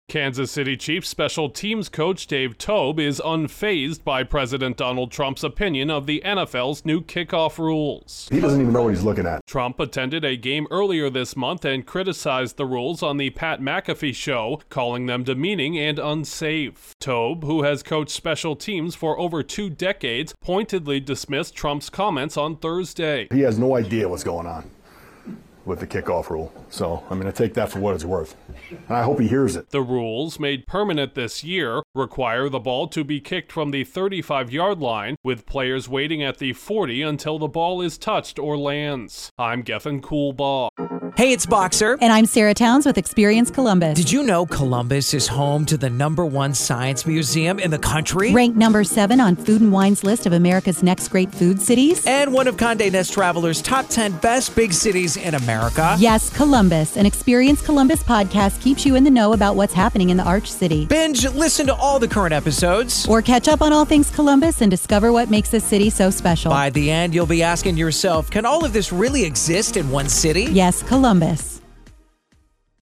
A longtime NFL coach is speaking out against President Trump’s criticism of the league’s new kickoff rules. Correspondent